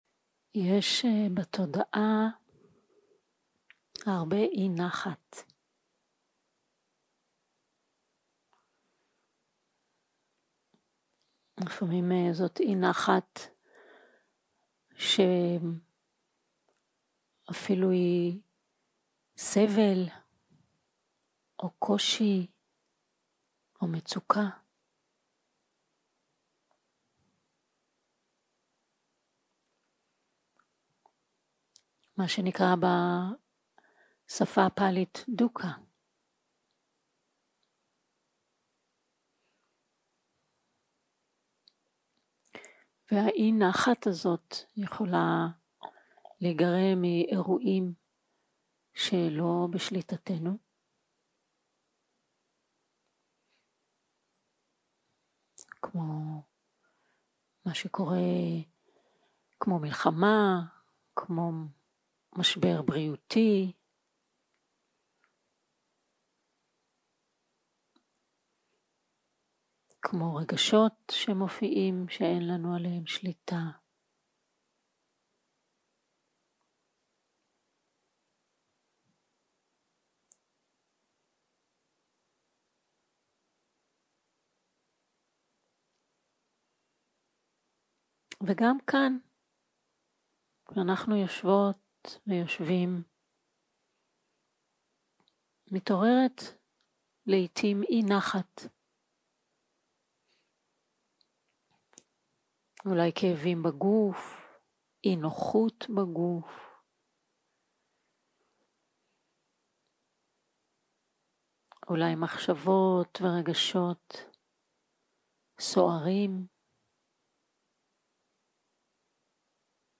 יום 2 - הקלטה 2 - בוקר - הנחיות למדיטציה - תשומת לב לגוף ולנשימה
סוג ההקלטה: שיחת הנחיות למדיטציה